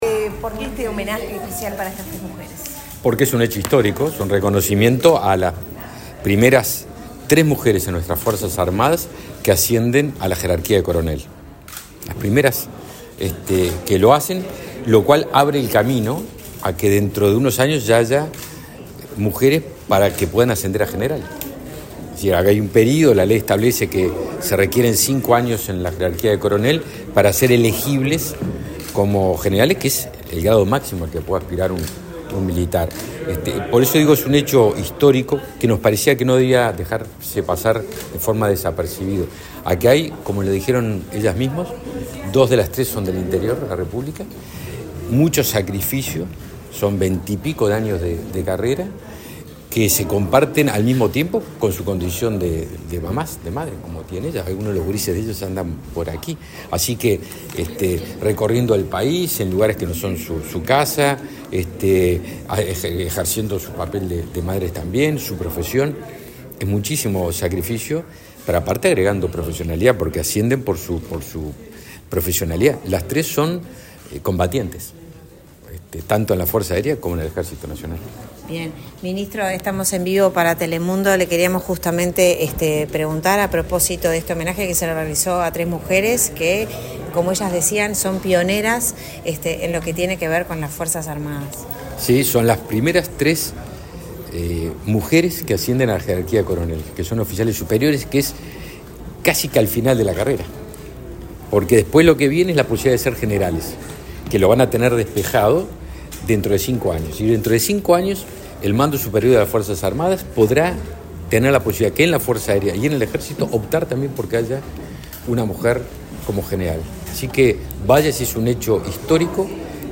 Declaraciones del ministro de Defensa Nacional, Javier García
Luego dialogó con la prensa.